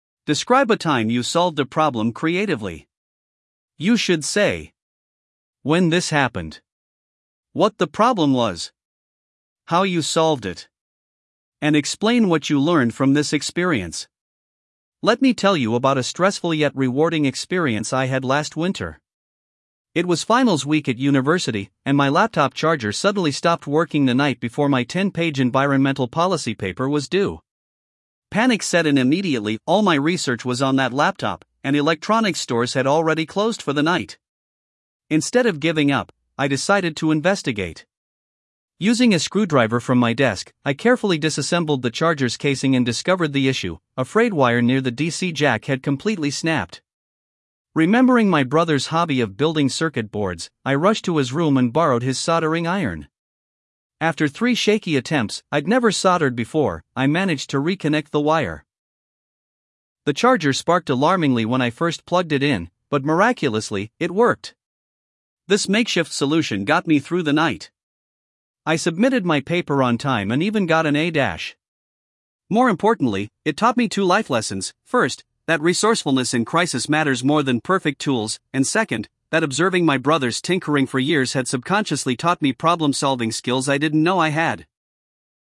Sample Response (Band 8.5+):
• Natural storytelling flow with signposting: “Instead of giving up…”, “More importantly…”
• No repetition or self-correction.
• Clear stress on key words (“miraculously,” “subconsciously”)
• Natural intonation showing emotion (panic → pride).